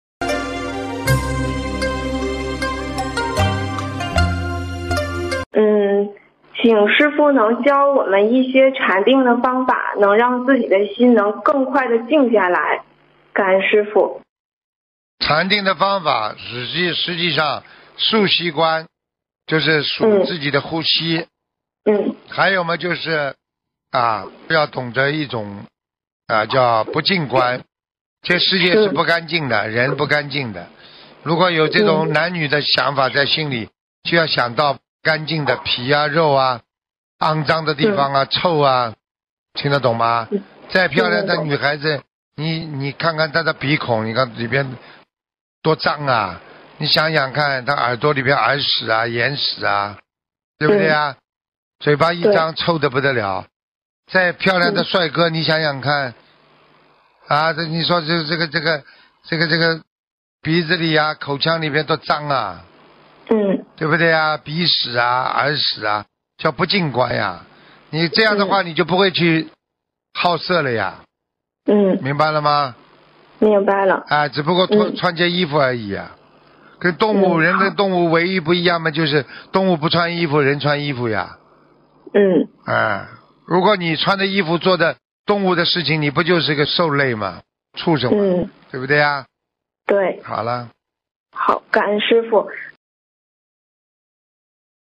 问答2019年8年2日！